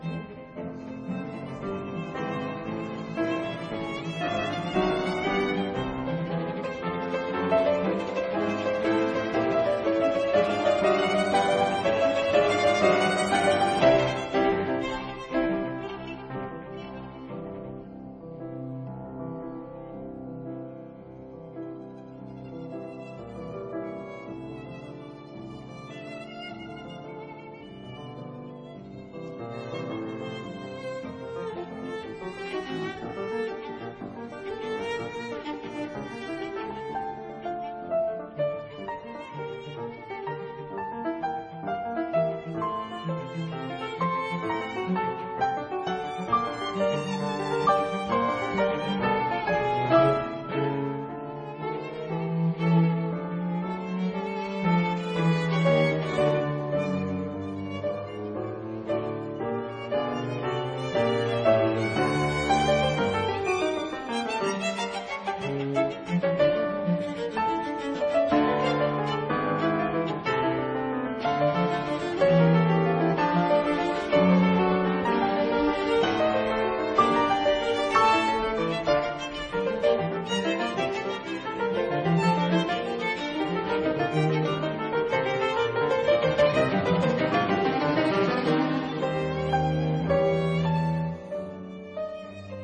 但優雅古典，動人自然，可以播放一整天。
其中的第二號，活潑又溫暖，重滿陽光。